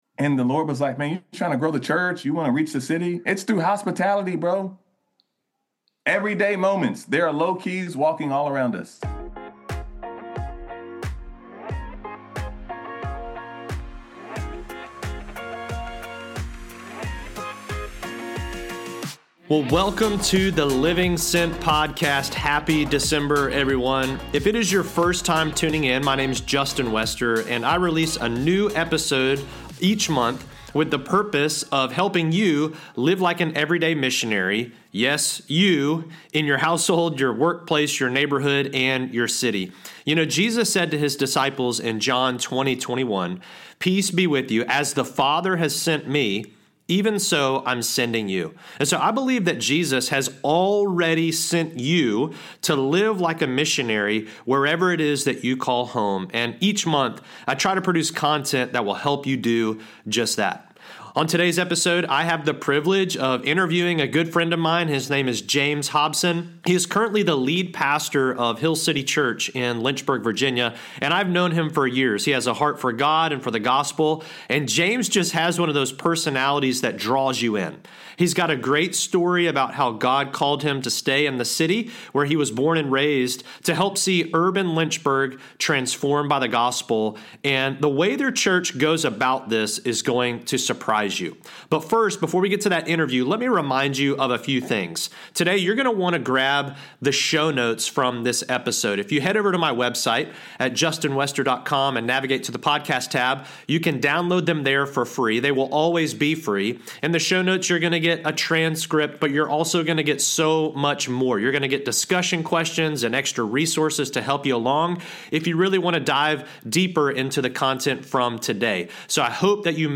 Continue reading Interview